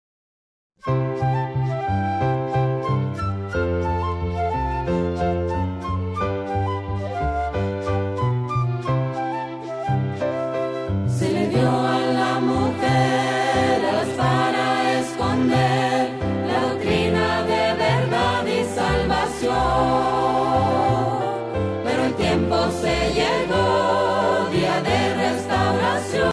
primer CD coral